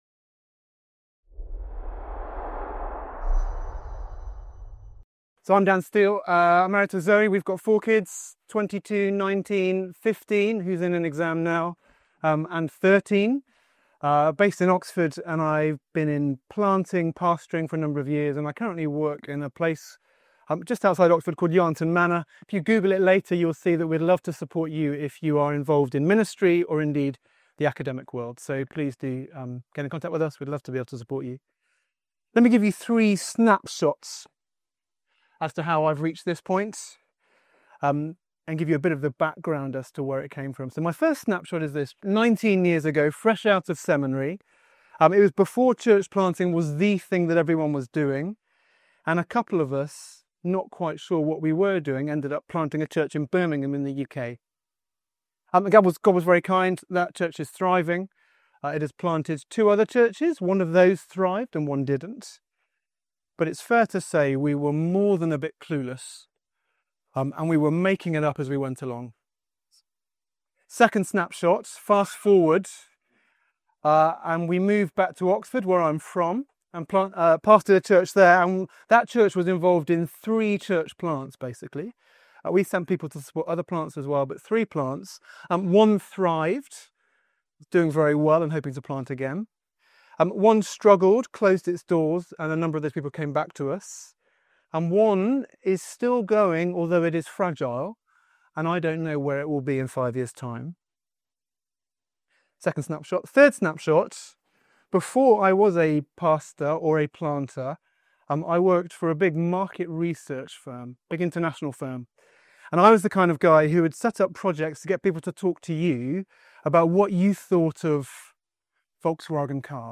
This talk presents findings from a research study examining 80 failed or struggling church plants across 27 countries. We explore key lessons for planting healthier churches—and equipping healthier planters—that are more likely to thrive long-term.
Event: ELF Workshop